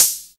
HH HH 73.wav